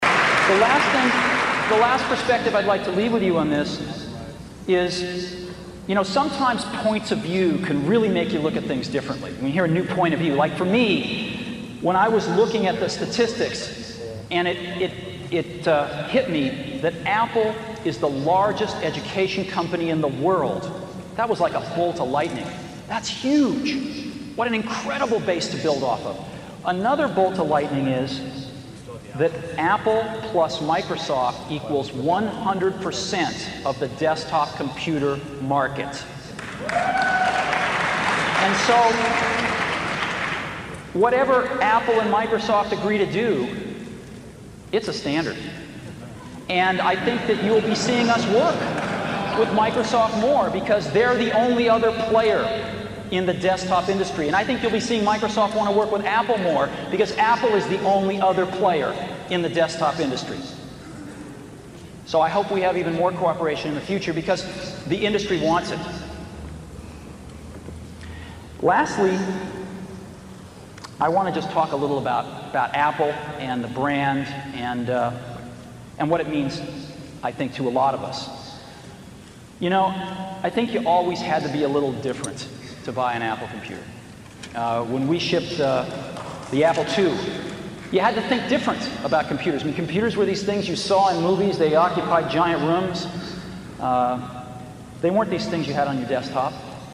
财富精英励志演讲 第101期:在疯狂中我们看到了天才(13) 听力文件下载—在线英语听力室